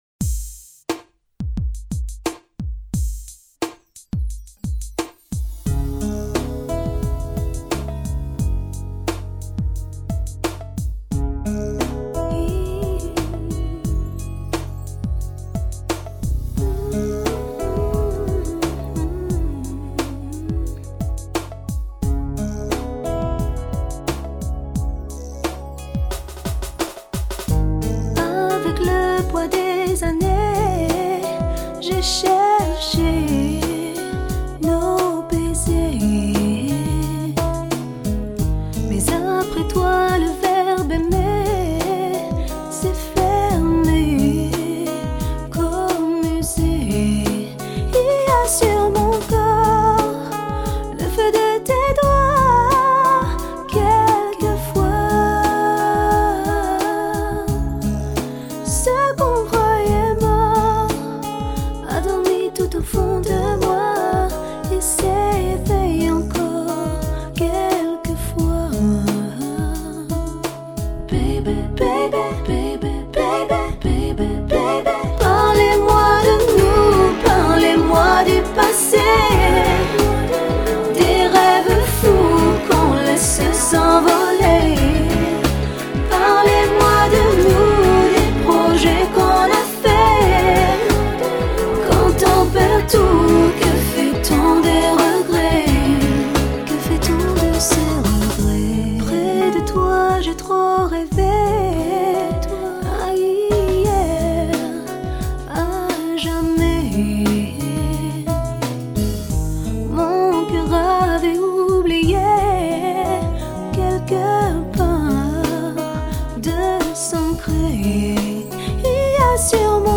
天使般的声音
的气质，天使般的嗓音，她用那诗意般的声音将古典音乐吟
纯美如天使的女声。幽雅沉静，华丽端庄，
般，甜美的哼鸣，唱诗般的曲调，使人回味悠长。